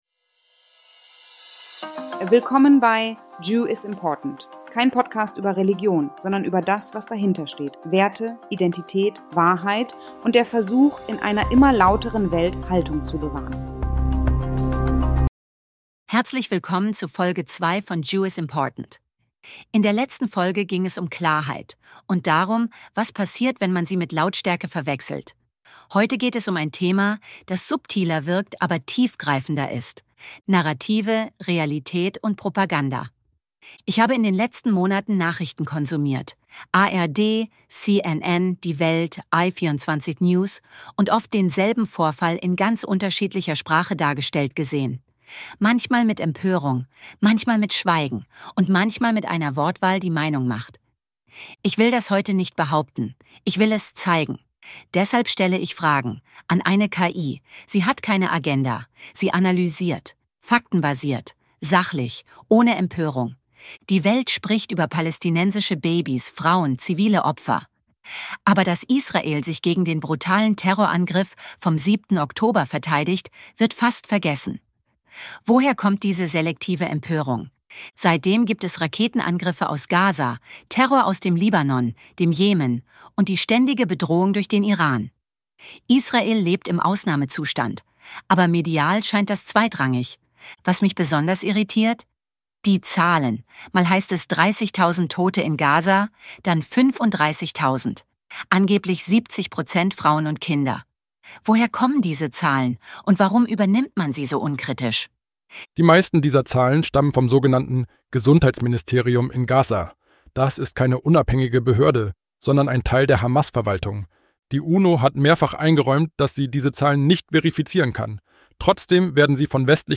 Im Mittelpunkt stehen gezielte Desinformation, mediale Verzerrung und strukturelle Manipulation im Kontext Israels. Ein Gespräch über Quellen, Schlagzeilen, humanitäre Hilfe und politische Erzählungen – und über die Auswirkungen von Mehrheitsmeinung auf Wahrheit.